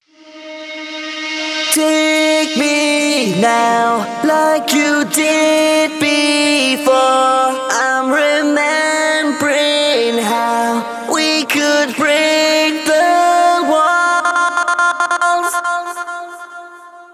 Vocal-Hook Kits